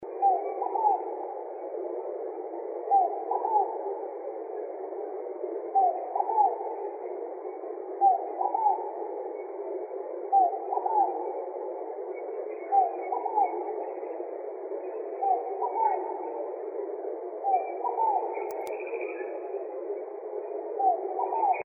Torcacita Escamada (Columbina squammata)
Nombre en inglés: Scaled Dove
Localidad o área protegida: Reserva Privada San Sebastián de la Selva
Condición: Silvestre
Certeza: Vocalización Grabada